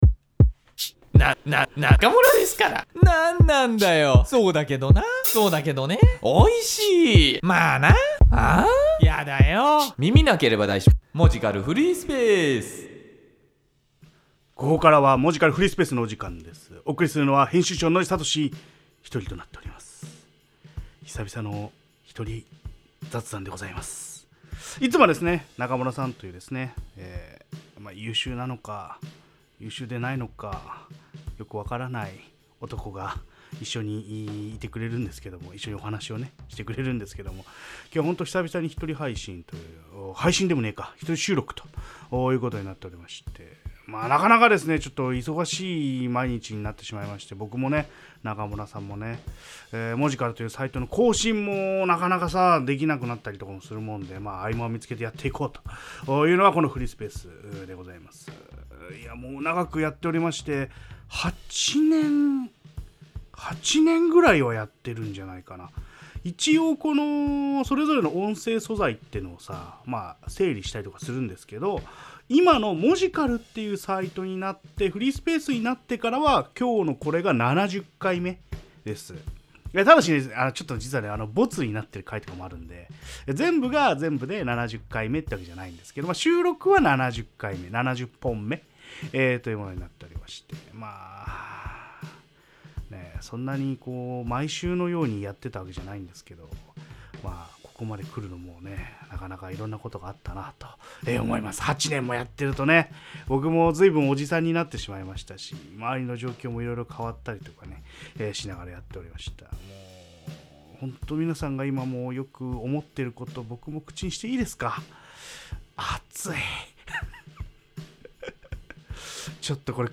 久しぶりの1人雑談